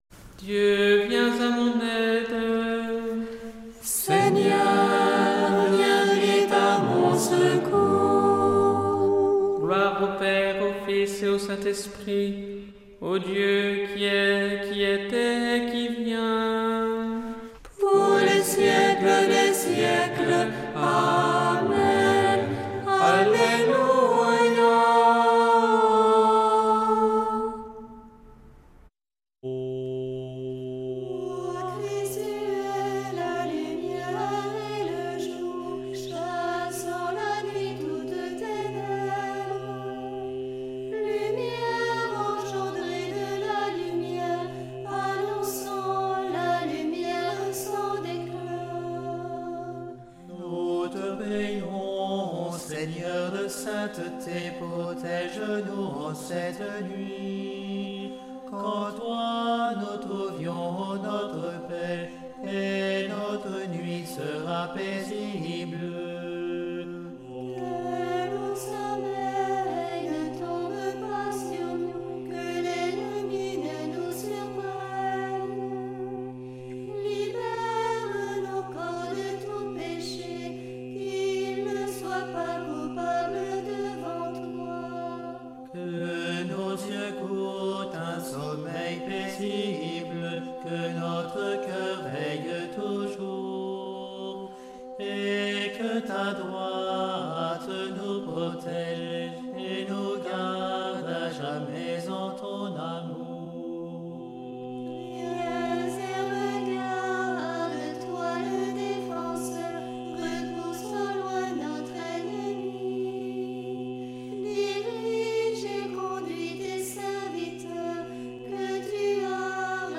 Une émission présentée par Groupes de prière